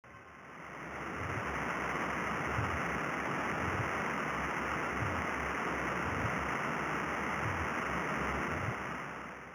¶ Paired Doves Description: Paired doves glitches appear at low frequency, and look somewhat like chirps, but they alternate between increasing in frequency and decreasing in frequency. These glitches were identified by volunteers of the Gravity Spy project, and are believed to have been related to 0.4 Hz motion of the beamsplitter at LIGO Hanford.